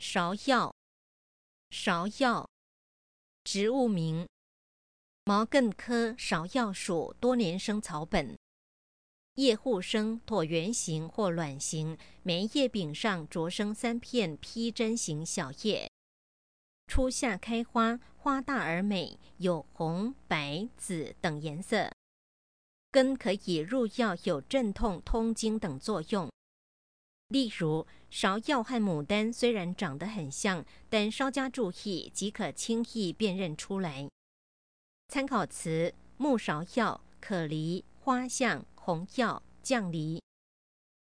Advanced Information 【芍】 艸 -3-7 Word 芍藥 Pronunciation ㄕㄠ ˊ ㄧㄠ ˋ ▶ Definition 植物名。